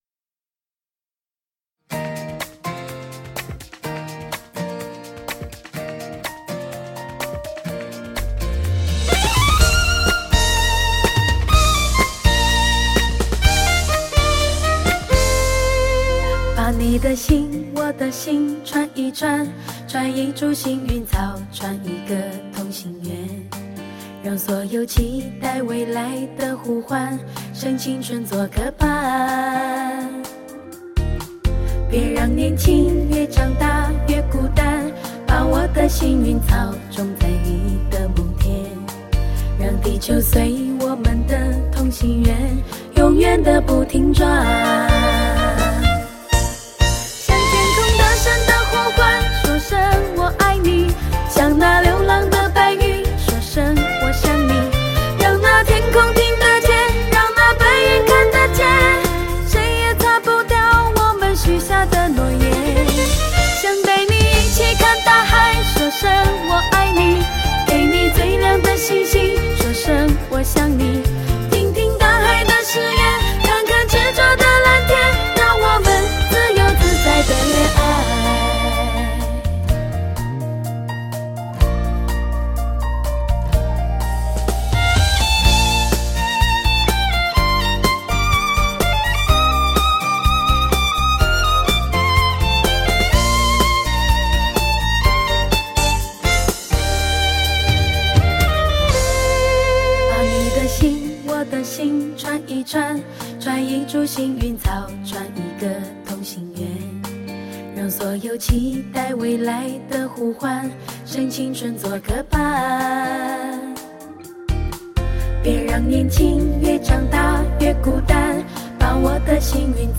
感动心灵的吟唱，让旋律再次勾起你心灵不同的感触！
非同凡响的纯情演绎，醇厚，细腻，通透，绝美无比！